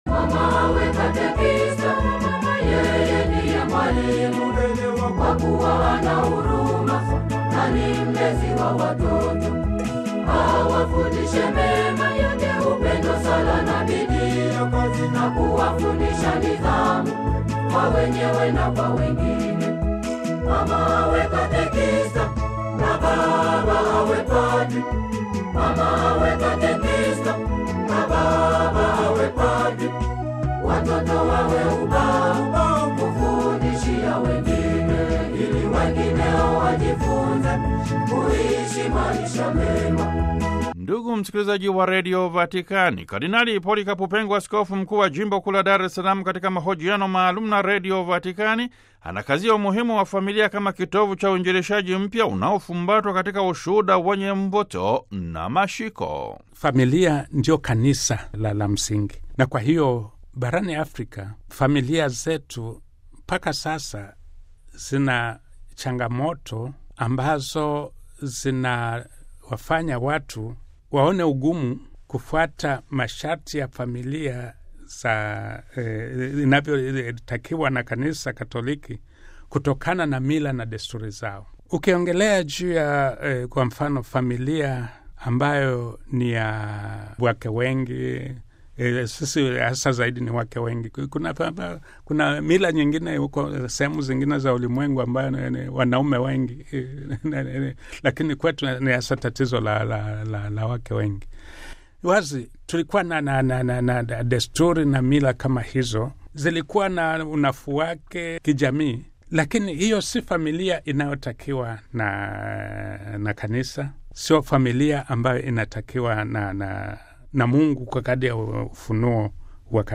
Hii ni sehemu ya mahojiano maalum kati ya Radio Vatican na Kardinali Polycarp Pengo, Askofu mkuu wa Jimbo kuu la Dar es Salaam.